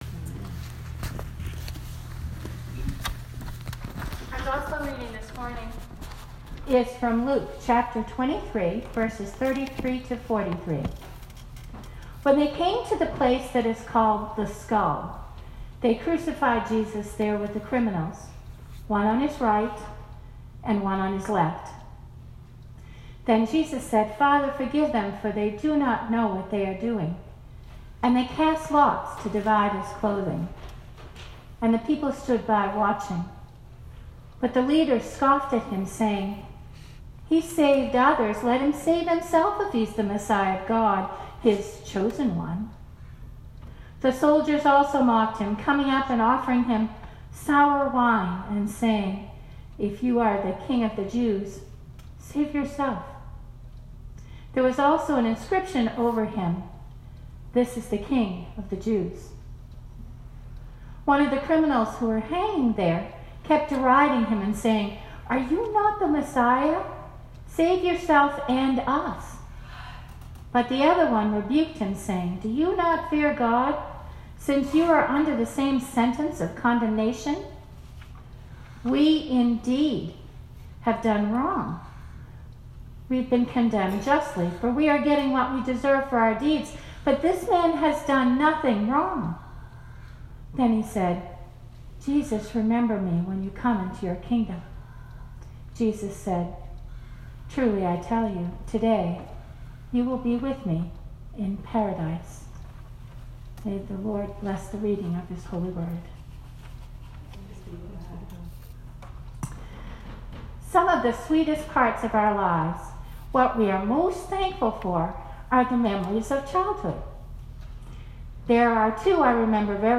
Sermon 2019-11-24